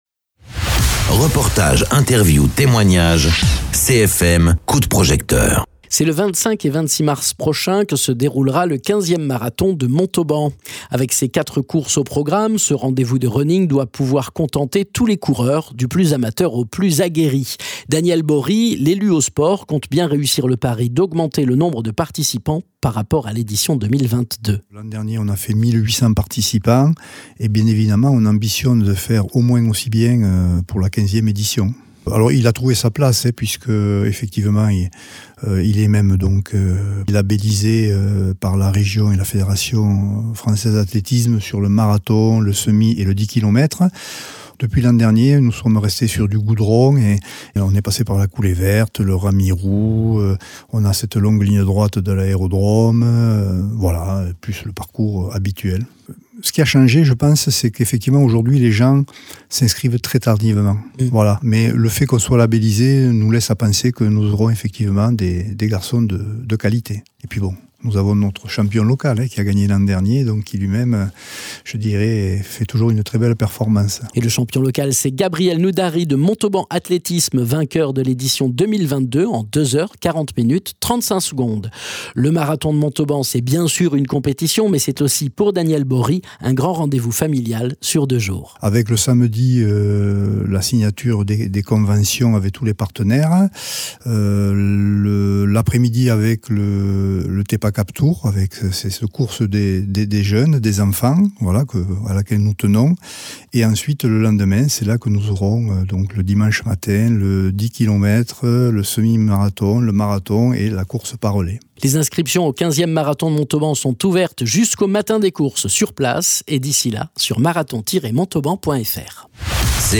Interviews
Invité(s) : Daniel Bory